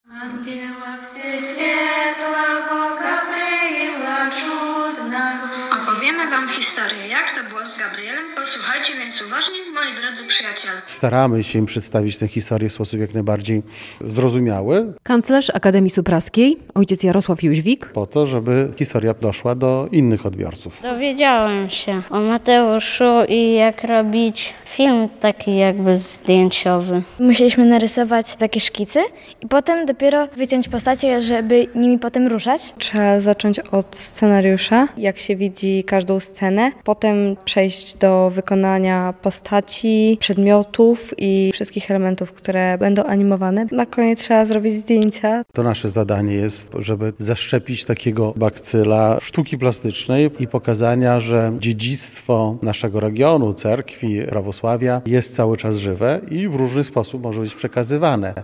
Warsztaty prawosławne - relacja